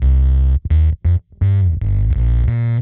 Index of /musicradar/dub-designer-samples/85bpm/Bass
DD_PBassFX_85C.wav